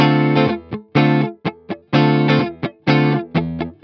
19 GuitarFunky Loop D.wav